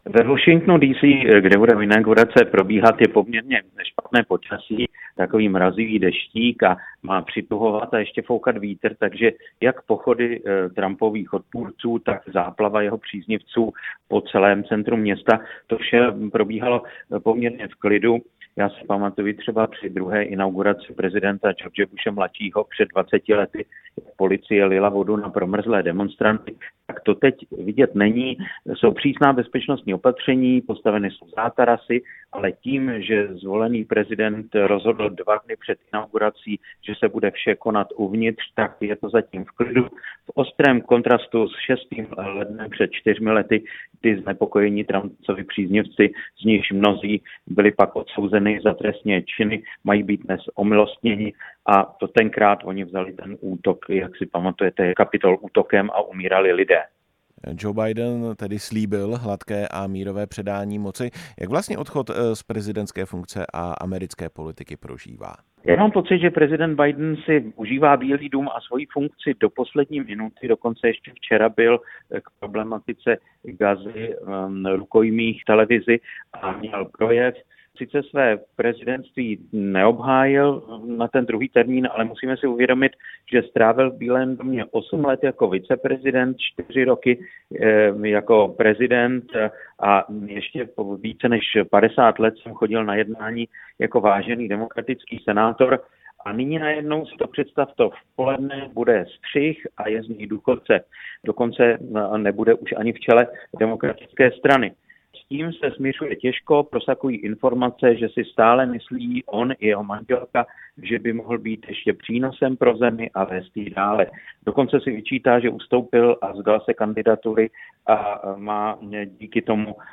Moderátor a zprávař